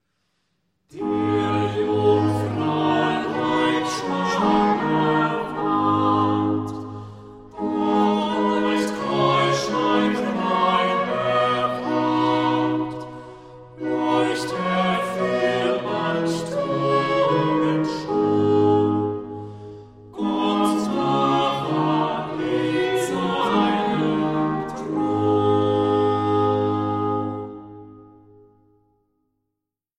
Leitung und Posaune